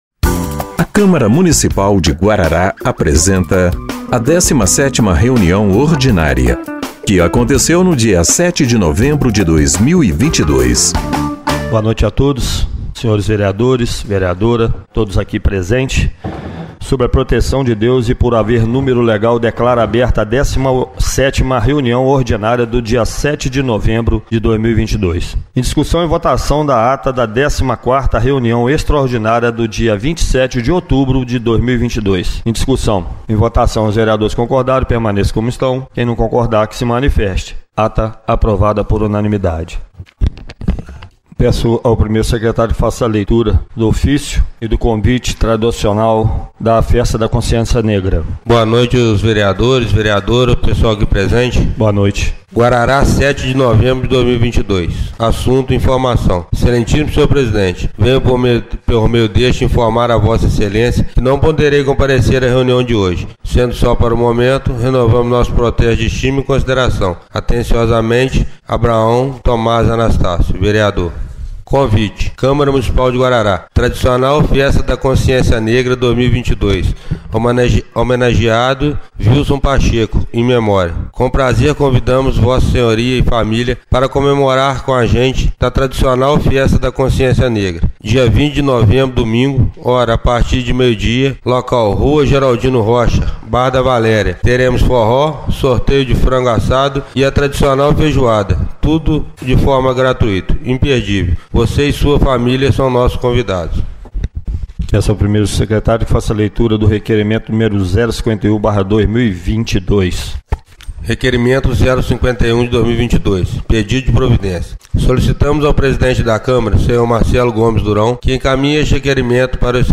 17ª Reunião Ordinária de 07/11/2022